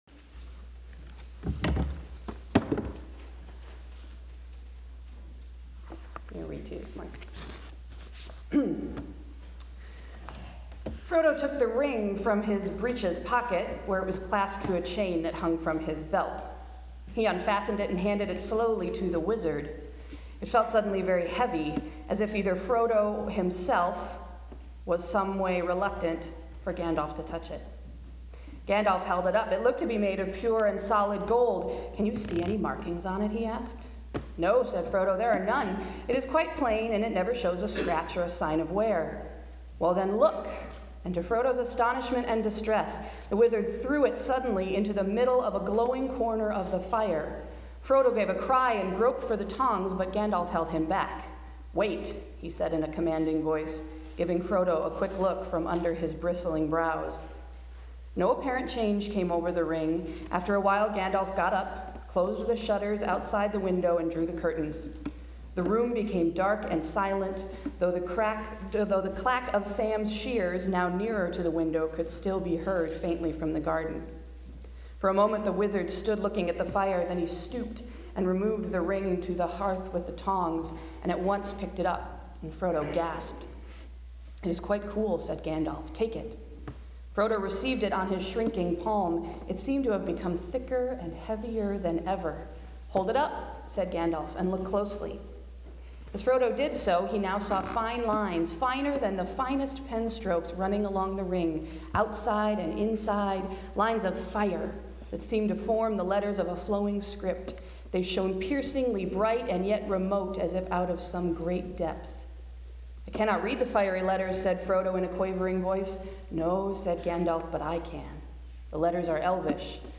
Sunday’s sermon: One Promise to Rule Them All